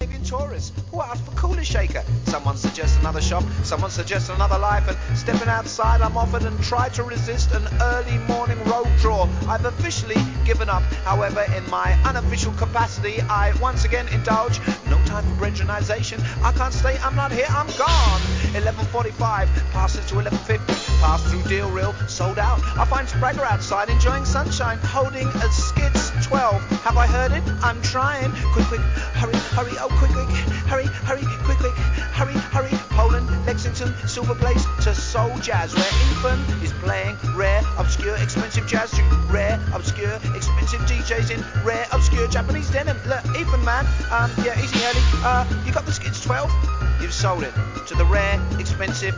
疾走感あるジャズ演奏を軸にポエトリーSTYLEのCROSS OVER!!